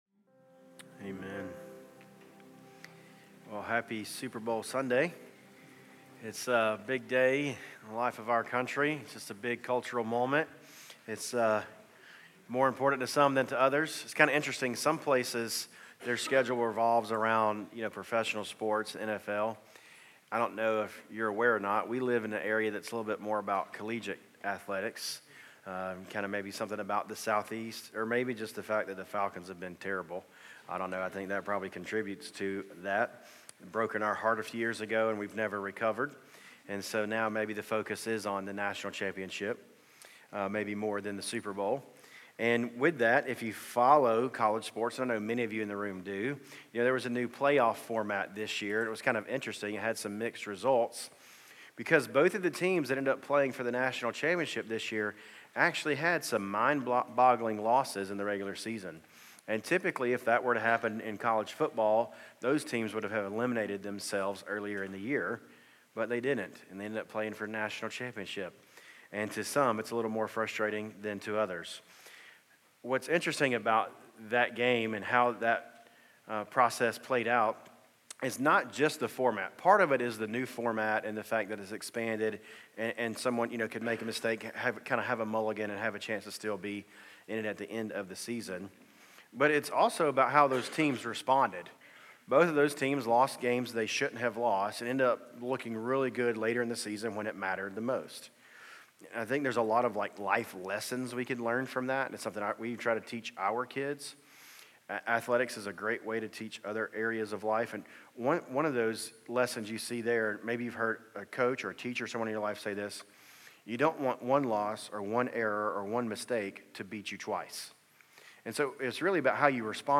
City View Church - Sermons A God of Second Chances Play Episode Pause Episode Mute/Unmute Episode Rewind 10 Seconds 1x Fast Forward 30 seconds 00:00 / Subscribe Share Apple Podcasts Spotify RSS Feed Share Link Embed